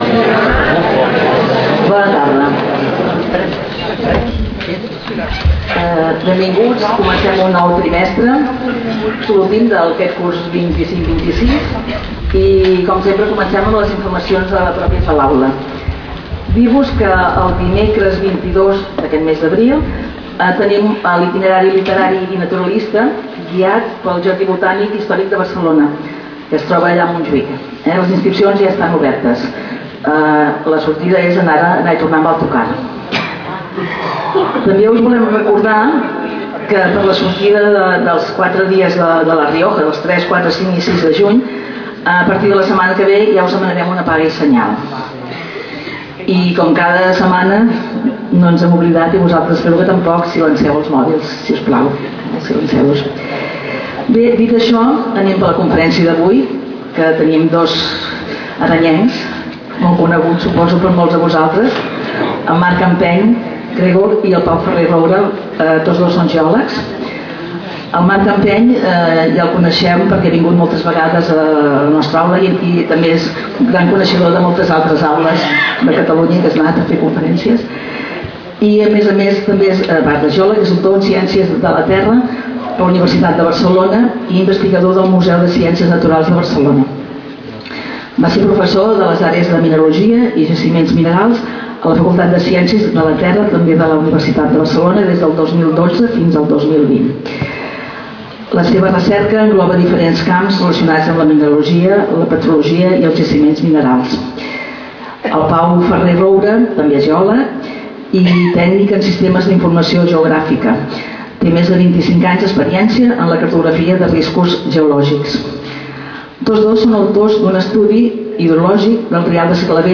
Lloc: Casal de Joventut Seràfica
Conferències